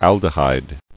[al·de·hyde]